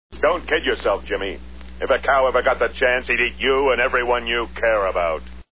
He says it during an educational video trying to convince kids to go against vegetarism.